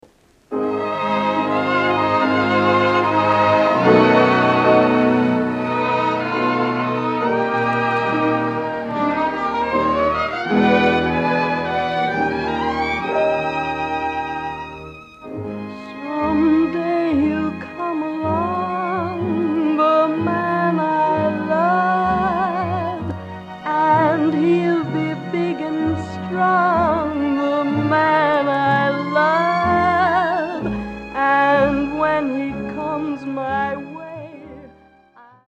female vocalists
Canadian popular and jazz music